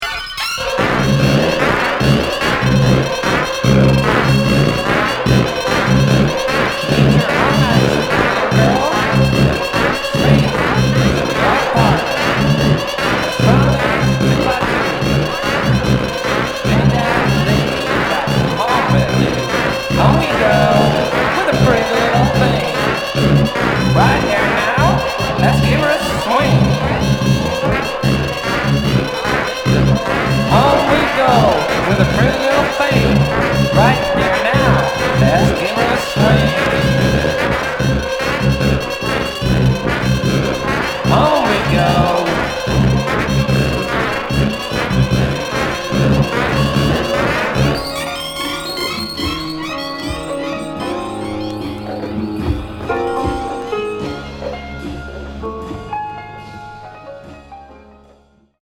キーワード：電子音　ミニマル